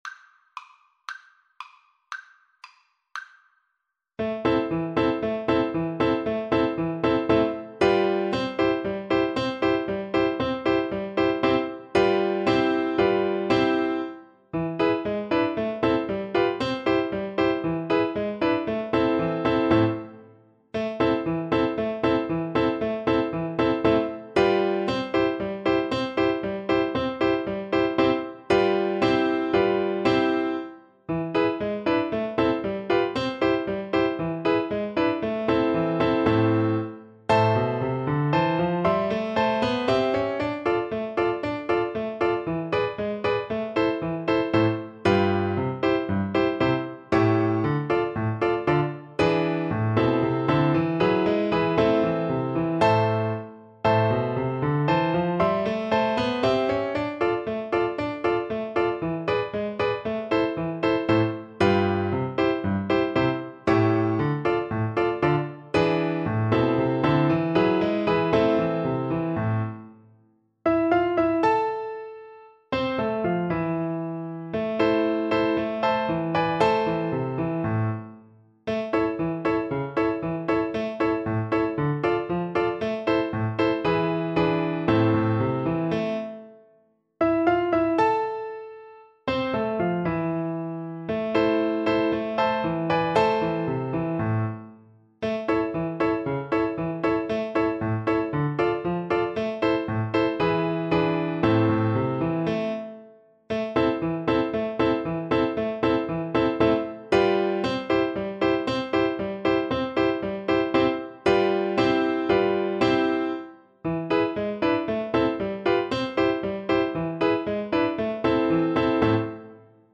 Allegro =c.116 (View more music marked Allegro)
2/4 (View more 2/4 Music)
world (View more world Viola Music)